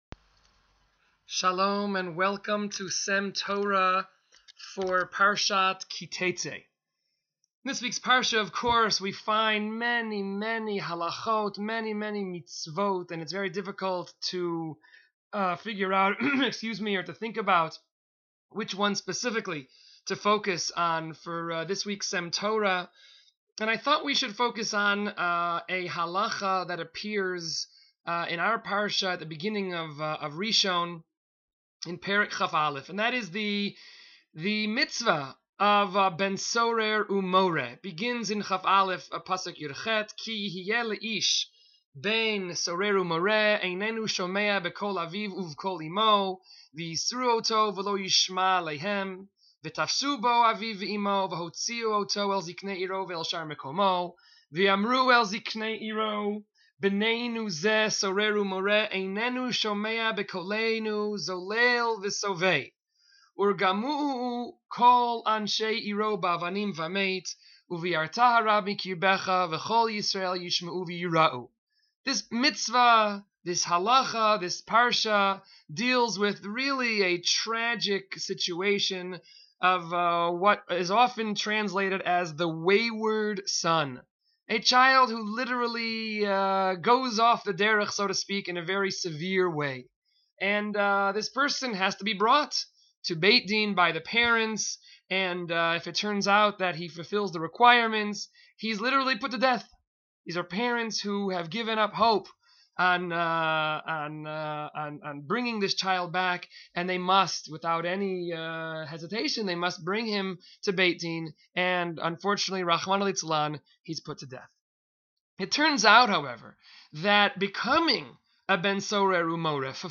S.E.M. Torah is a series of brief divrei Torah delivered by various members of the faculty of Sha�alvim for Women.